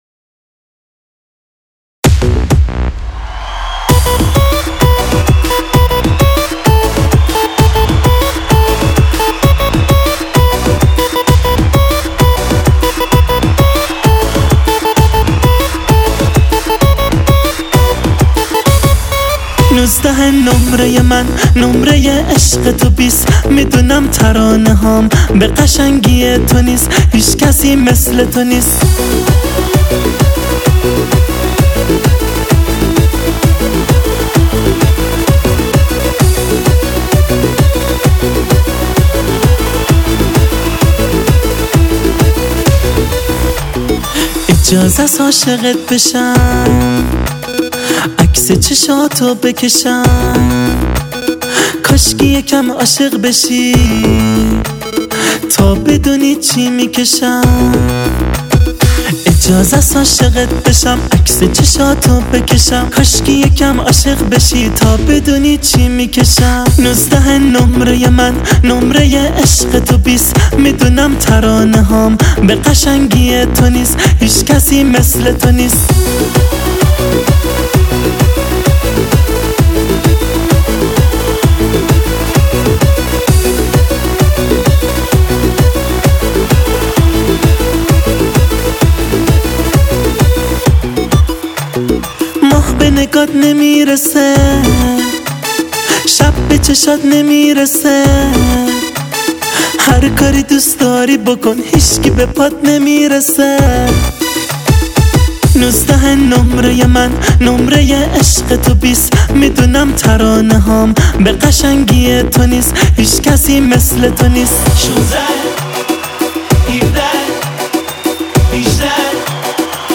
آهنگ شیش و هشت
اهنگ شاد ایرانی